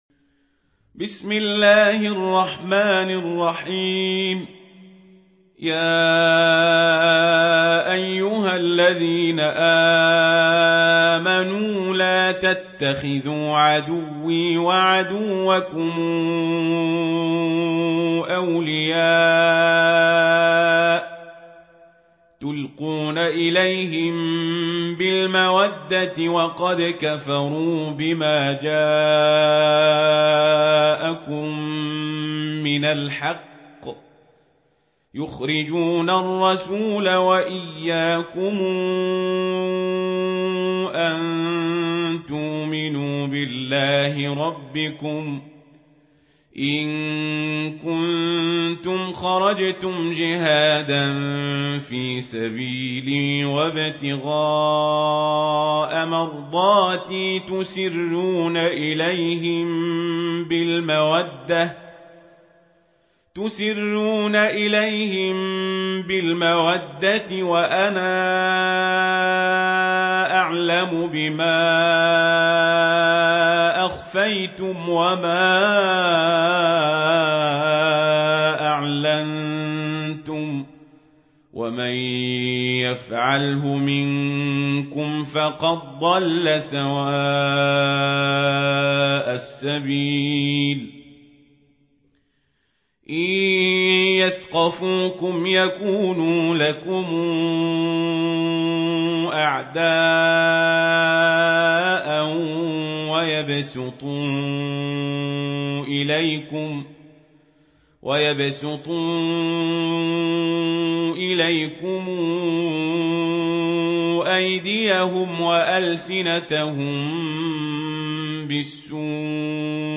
سورة الممتحنة | القارئ عمر القزابري